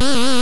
sfx_hurt.ogg